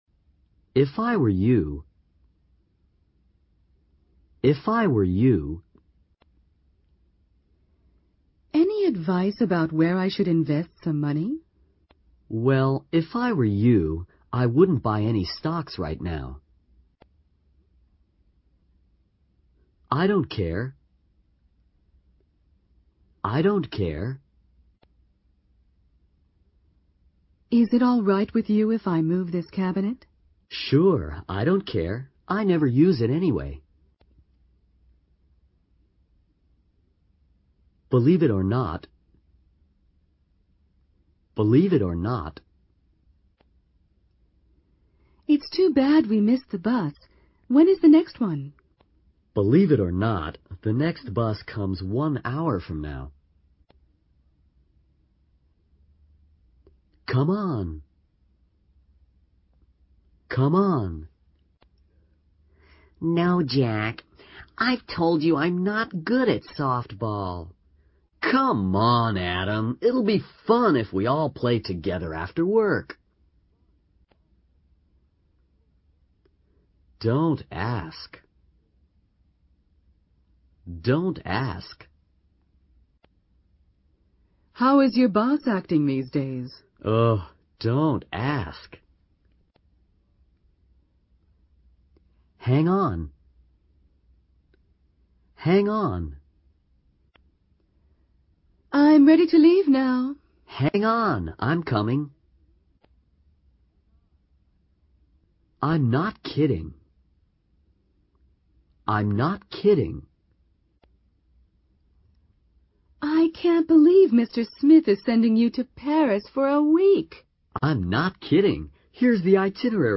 在线英语听力室英语口语常用短句(MP3+中英字幕) 第7期的听力文件下载,《英语口语常用短句》包含了纯正的英语发音以及常用的英语口语短句，并附有中英字幕文件LRC，是学习初级英语口语，提高英语口语水平的必备英语材料。